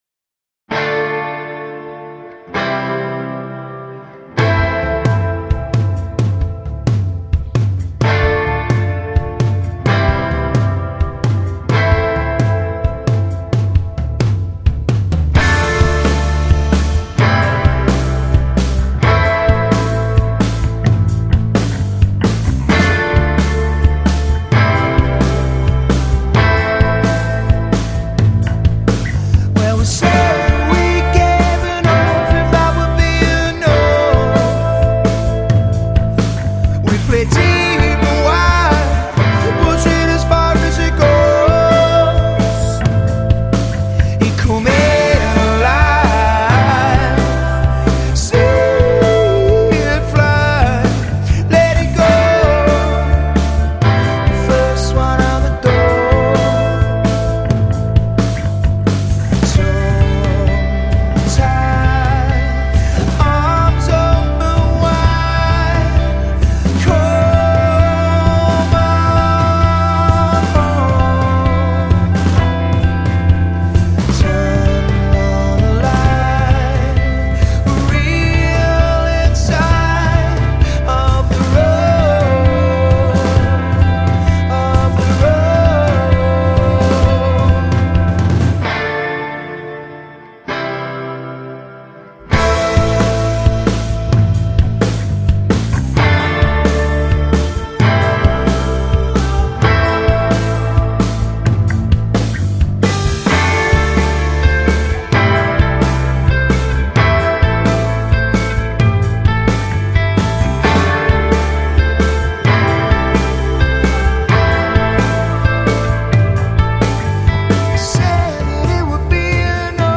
Indie-alt rockers